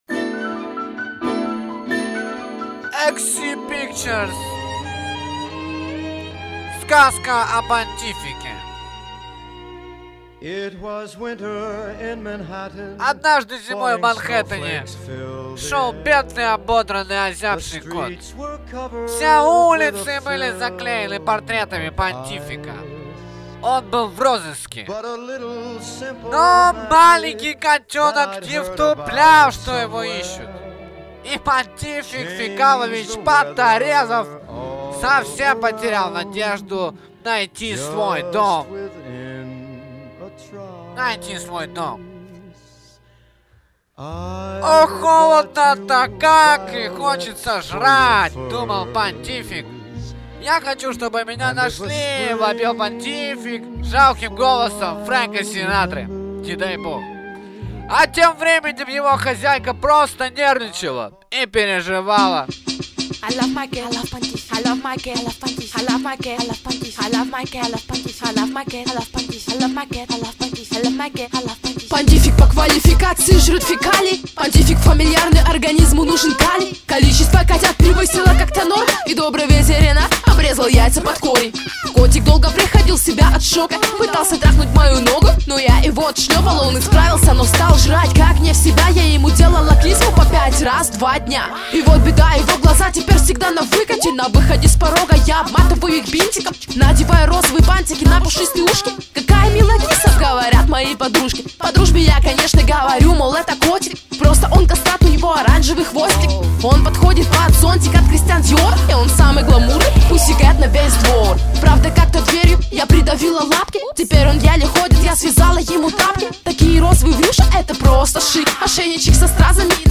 • Жанр: Рэп
BATTLE TRACK BATTLE TRACK